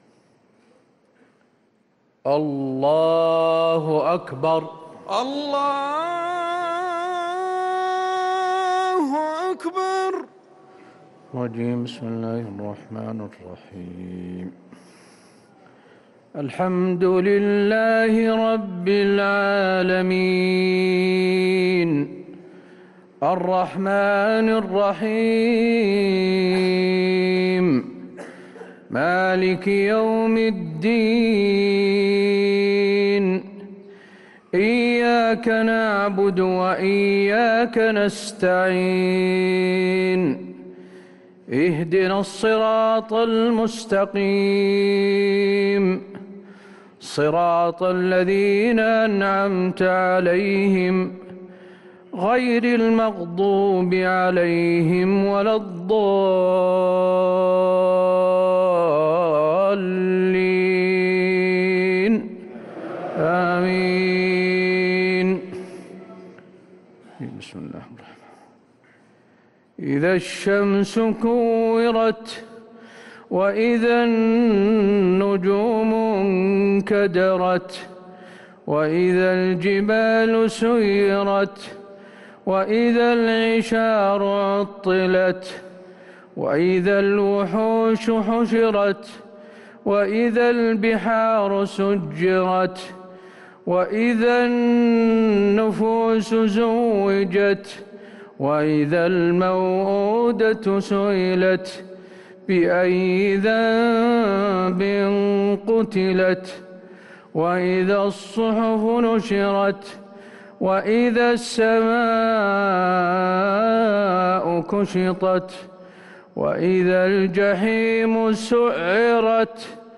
صلاة العشاء للقارئ حسين آل الشيخ 5 رمضان 1444 هـ
تِلَاوَات الْحَرَمَيْن .